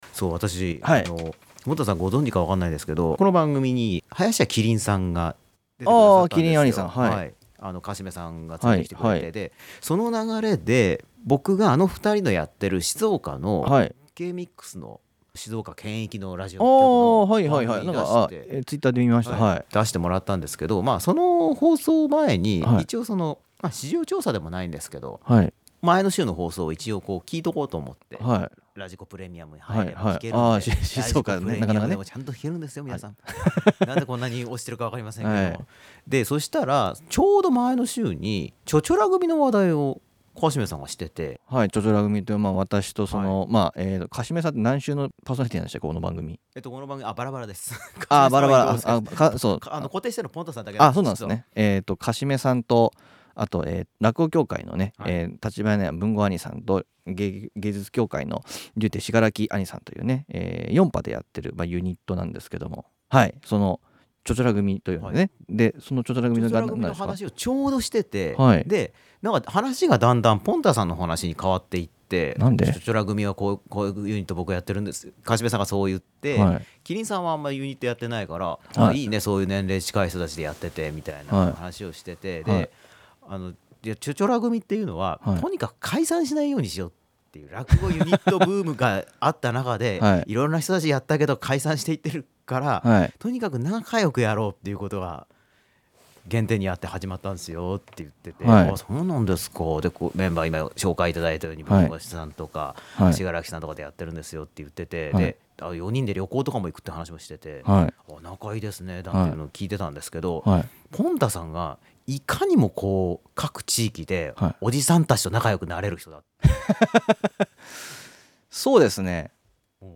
その代わり、ほぼ同じ理由でカットしたＯＰトーク(約10分弱)をこちらで公開いたします。